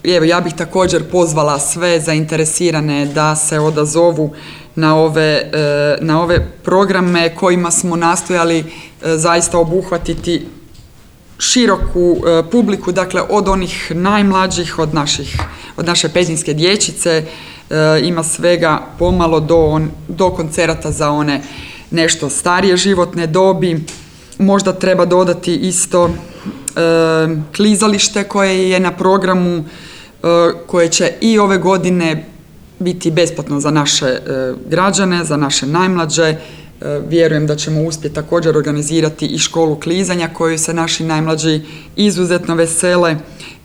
Petrinjce je na bogat i sadržajan program pozvala i gradonačelnica Magdalena Komes